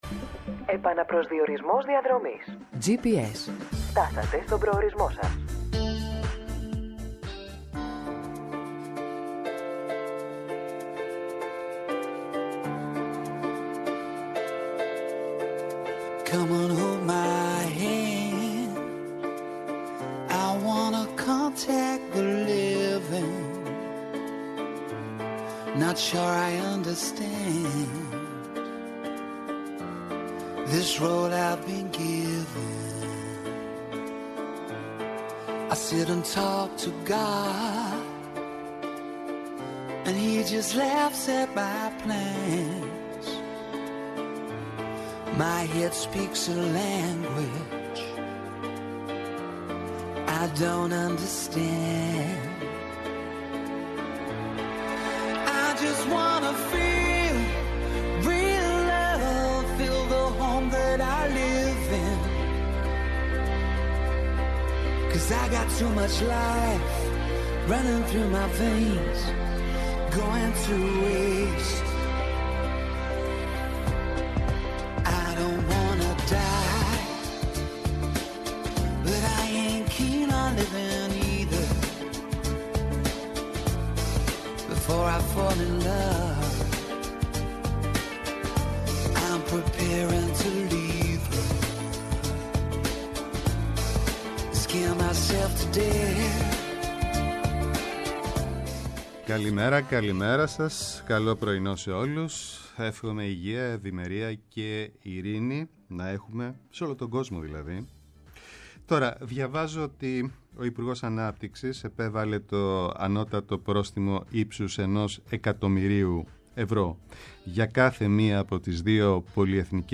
-ο Σωτήρης Αναγνωστόπουλος, γενικός γραμματέας Εμπορίου και Καταναλωτή